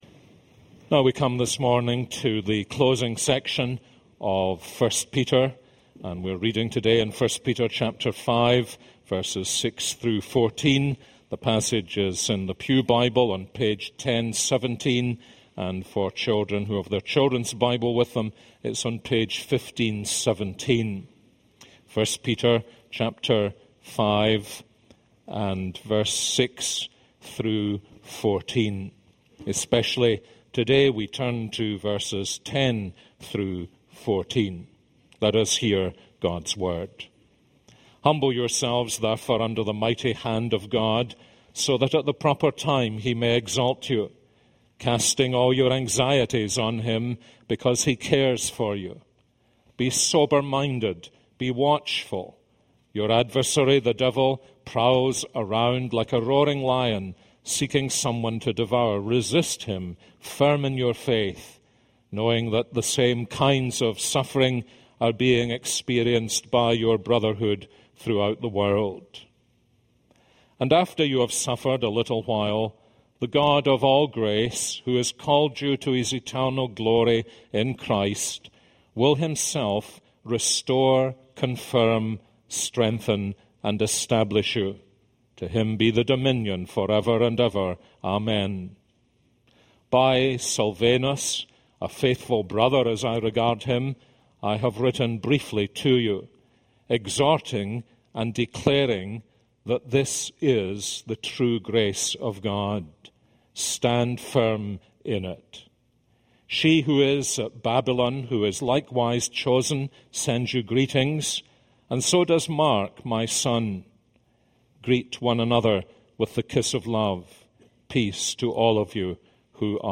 This is a sermon on 1 Peter 5:10-14.